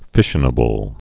(fĭshə-nə-bəl)